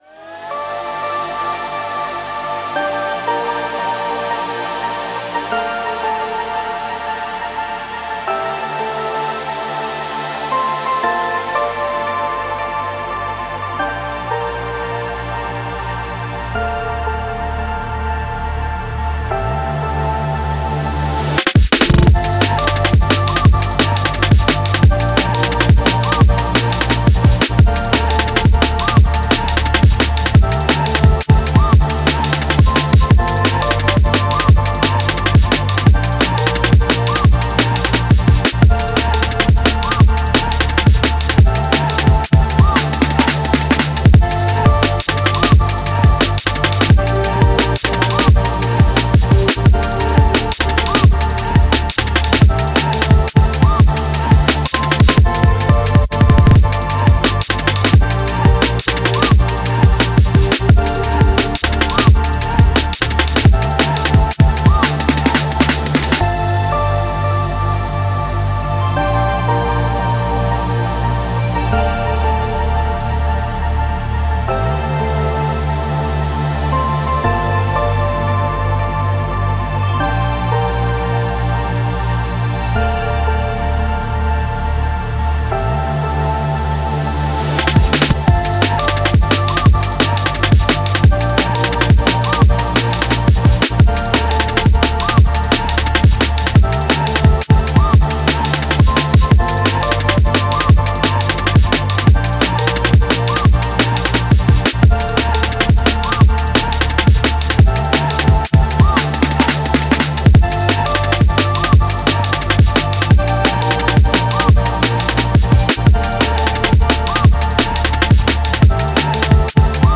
cool dnb/breakcore track